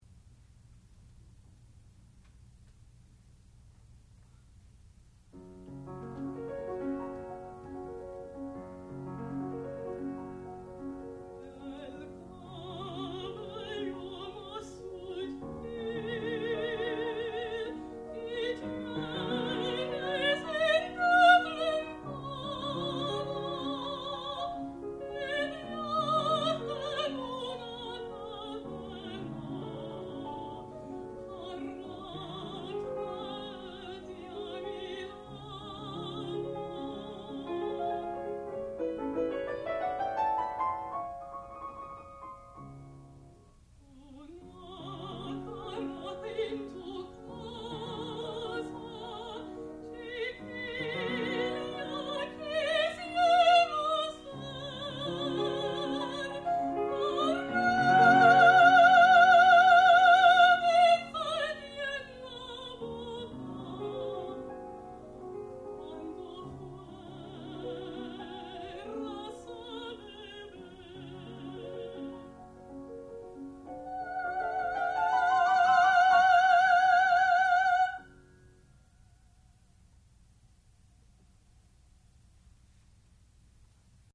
sound recording-musical
classical music
Master's Recital